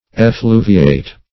Search Result for " effluviate" : The Collaborative International Dictionary of English v.0.48: Effluviate \Ef*flu"vi*ate\, v. i. To give forth effluvium.